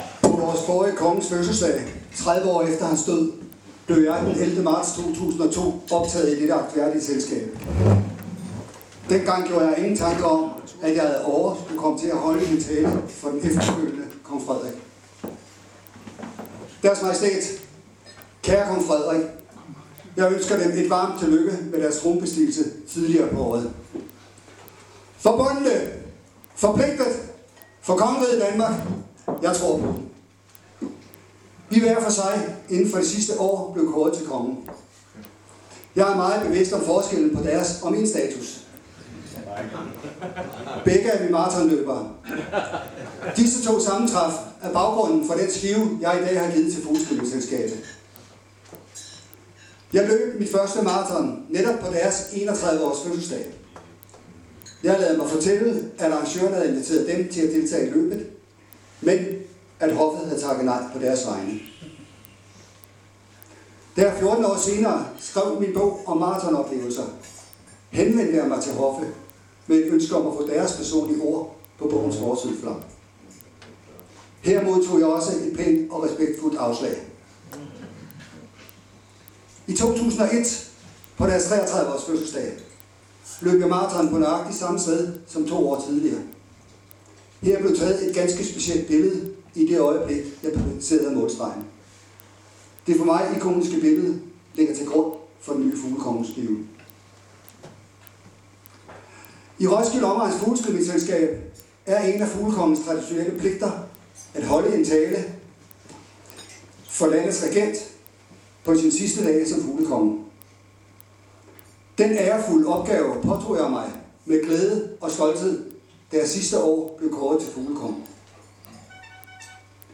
Under årets fugleskydningsfrokost afholdes fem taler. Først taler fuglekongen for landets majestæt.
Fuglekongens tale 2024.mp3